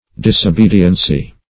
Disobediency \Dis`o*be"di*en*cy\, n.